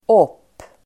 Uttal: [åp:]